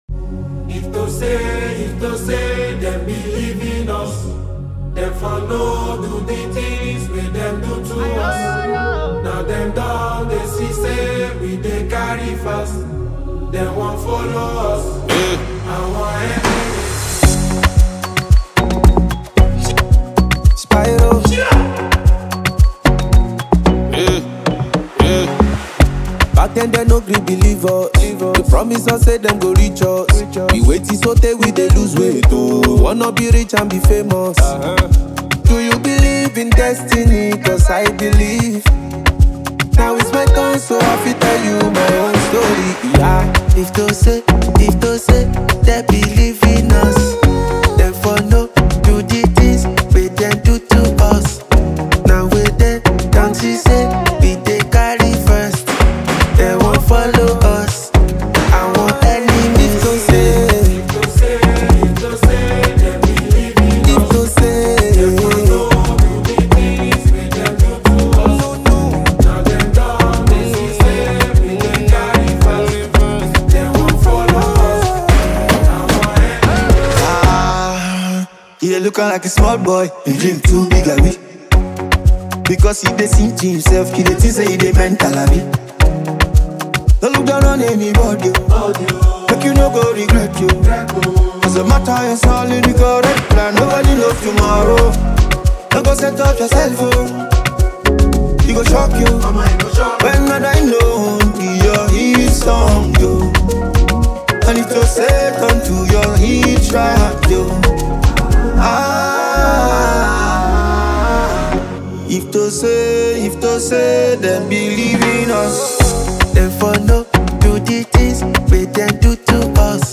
electrifying single
a soulful tune